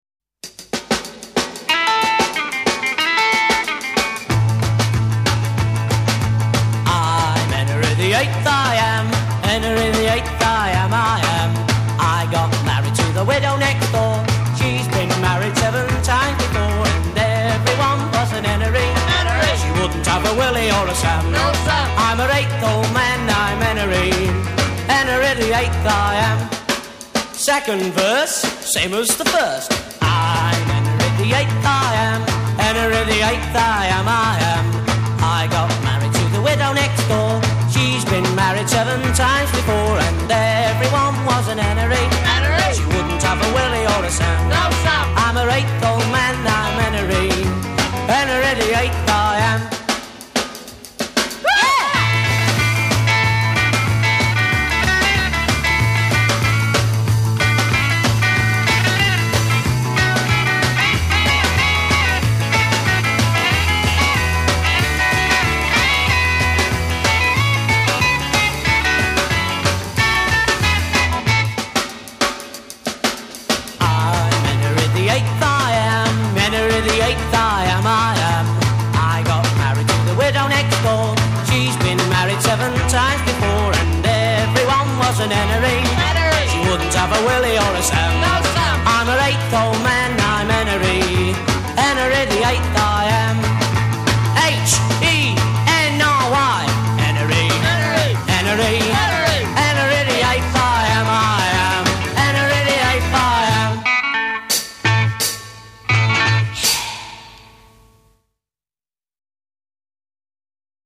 bass guitar
drums
intro 0:00 10 drums > ensemble
A verse 0: 36 vocal solo with choral response a
A verse : 36 guitar solo
coda : 20 repeat hook b